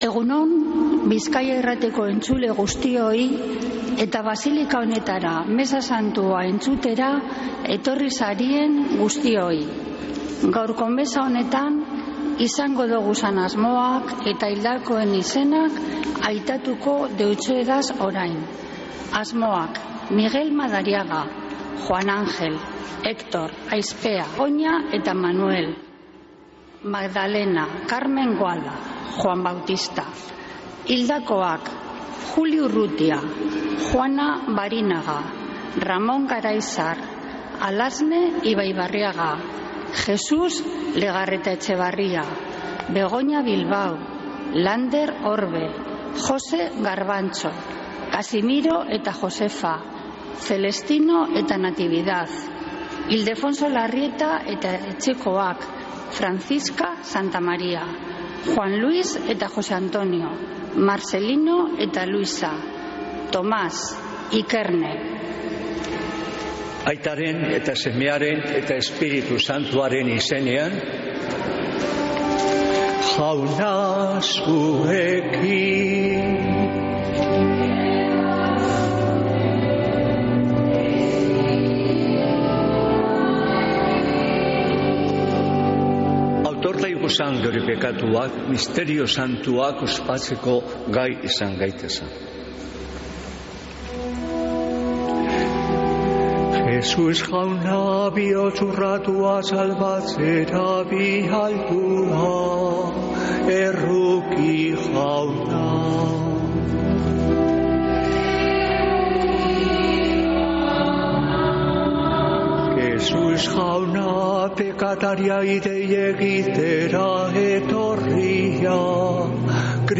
Mezea (25-09-01) | Bizkaia Irratia